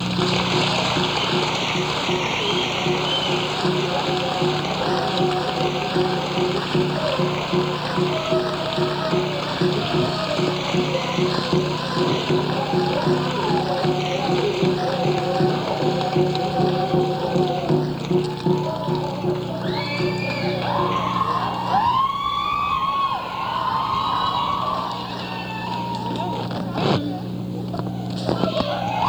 03. drums and chanting (0:29)